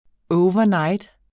Udtale [ ˈɔwvʌˈnɑjd ]